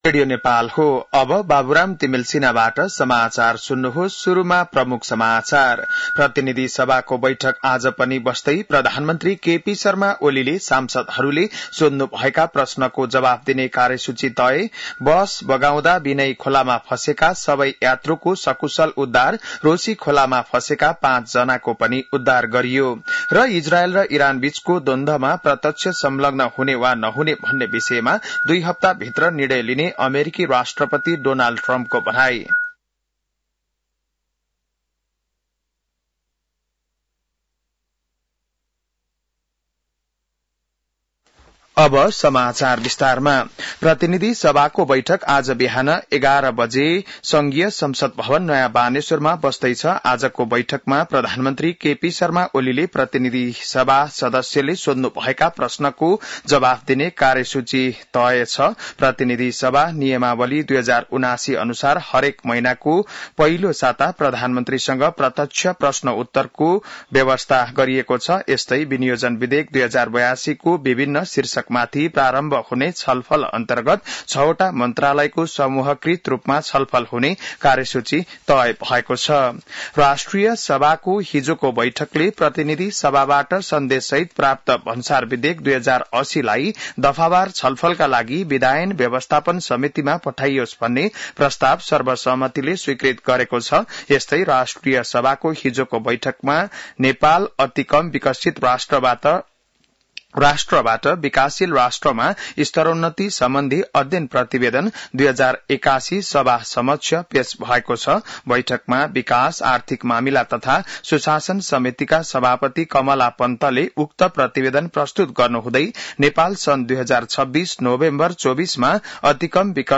बिहान ९ बजेको नेपाली समाचार : ६ असार , २०८२